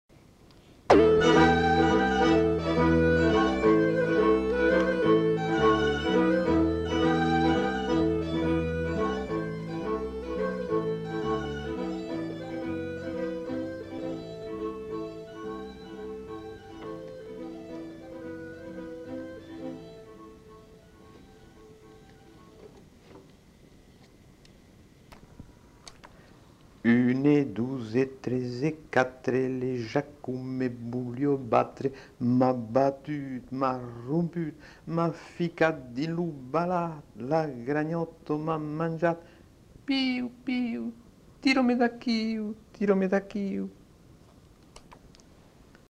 Genre : forme brève
Effectif : 1
Type de voix : voix d'homme
Production du son : récité
Langue : occitan (languedocien)
Notes consultables : Extrait musical en introduction.